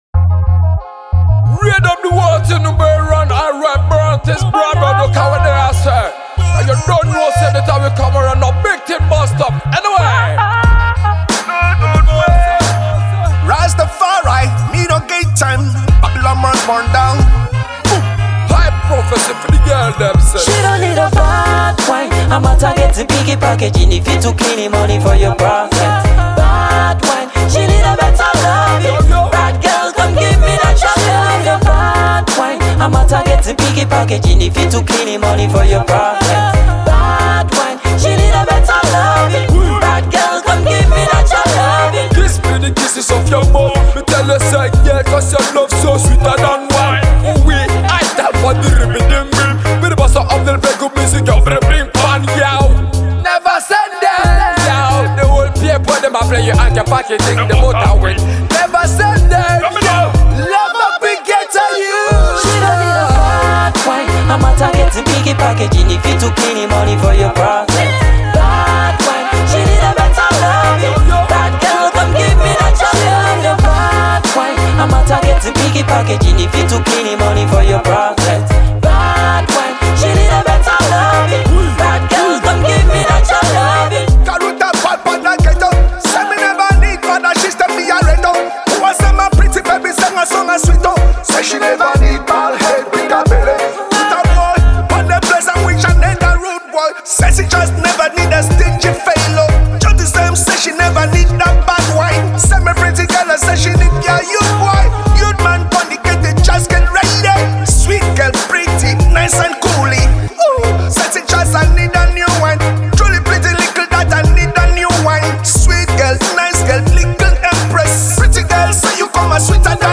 raggae sensation
the only jam of the raggae genre